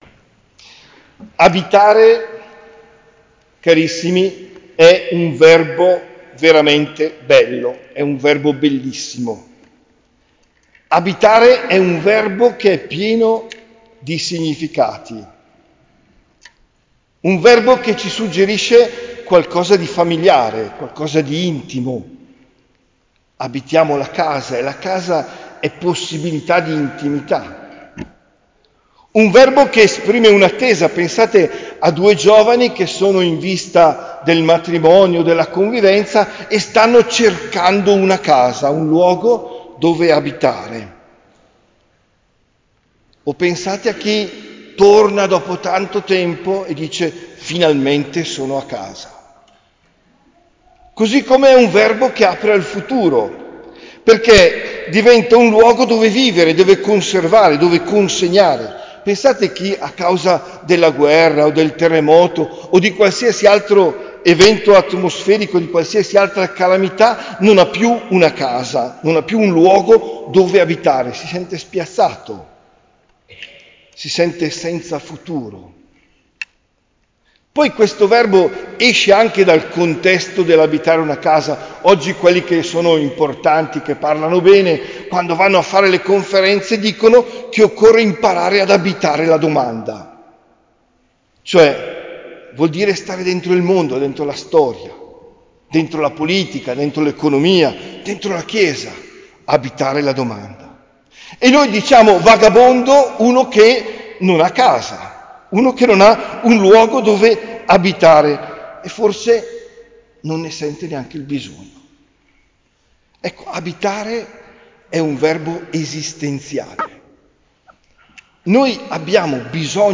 OMELIA DEL 5 MAGGIO 2024